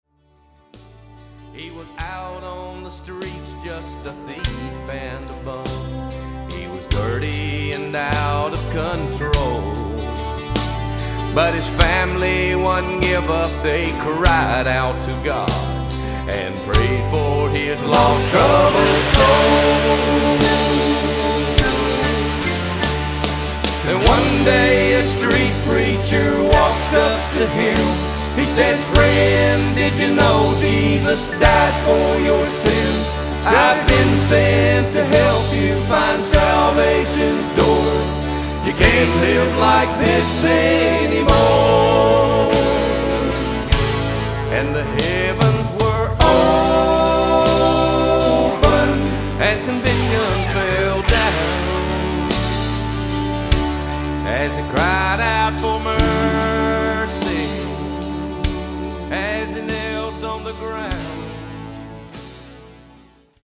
Autoharp
Drums, Lead and Harmony Vocals
Guitar
Bass
Keyboards
Rhythm guitar